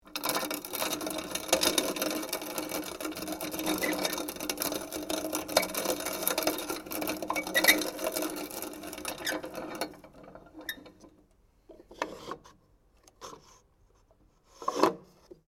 coffee grinder (unknown brand)